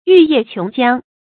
玉液瓊漿 注音： ㄧㄩˋ ㄧㄜˋ ㄑㄩㄥˊ ㄐㄧㄤ 讀音讀法： 意思解釋： 瓊：美玉。